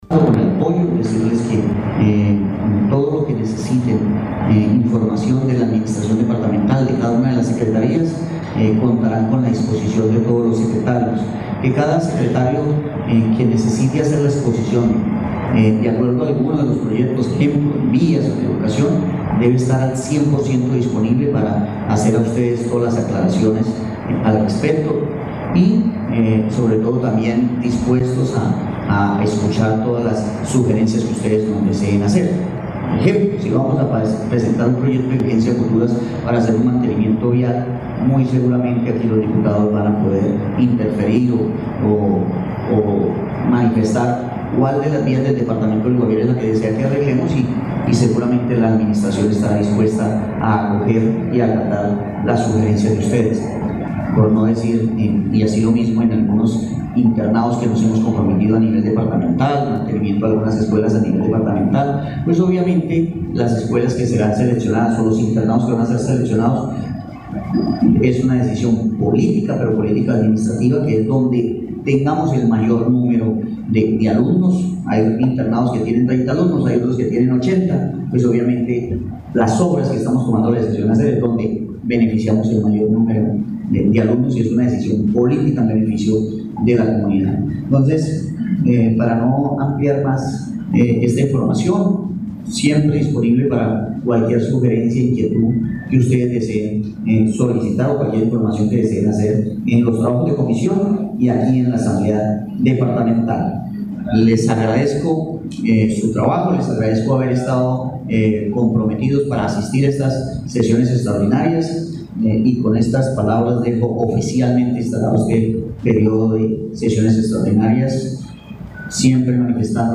Escuche a Heydeer Palacio, gobernador del Guaviare.